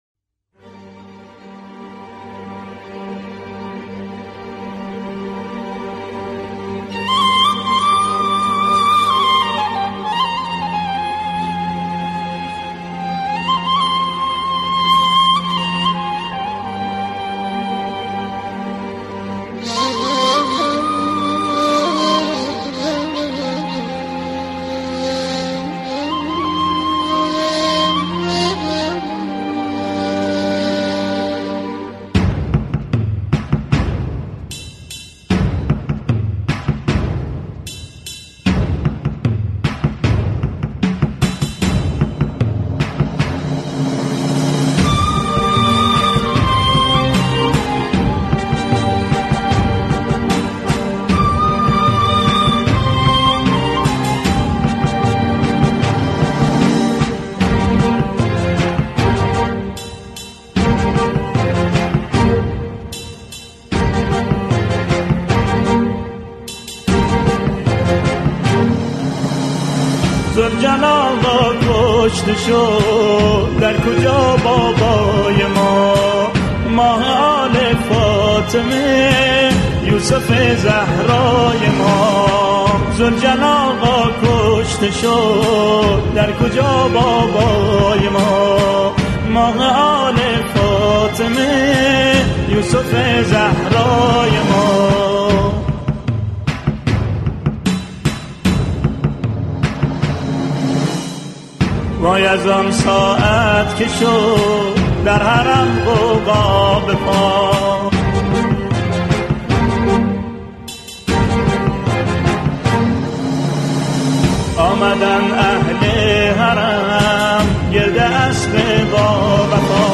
آلبوم مذهبی